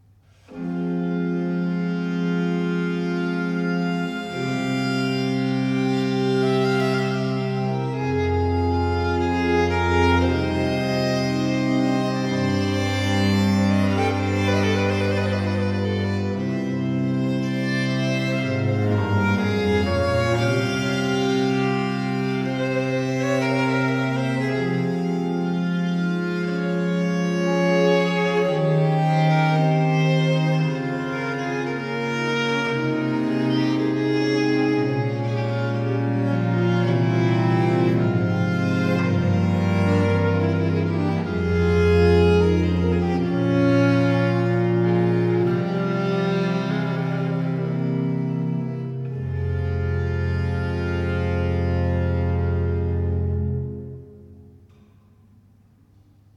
Largo